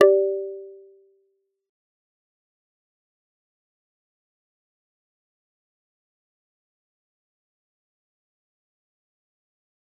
G_Kalimba-G4-f.wav